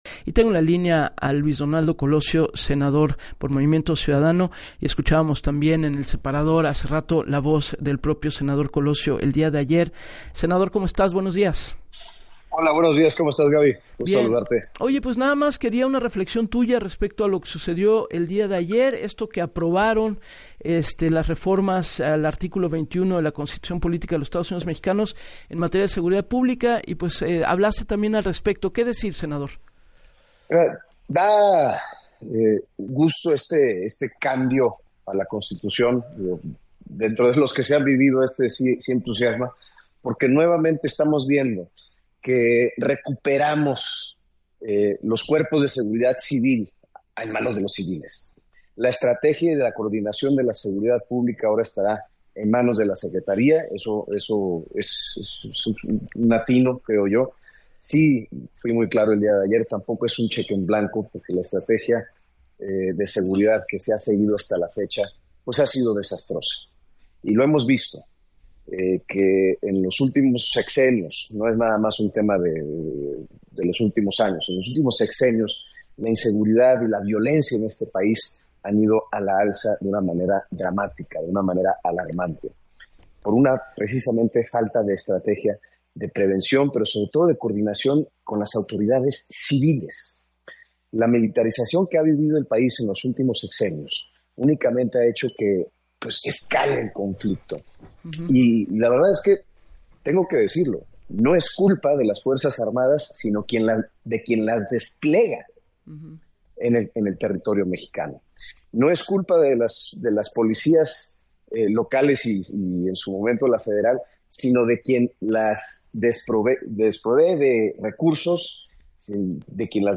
Reconoció en entrevista para “Así las Cosas” con Gabriela Warkentin, que la inseguridad y la violencia incrementaron “por falta de estrategia, coordinación y autoridades civiles”.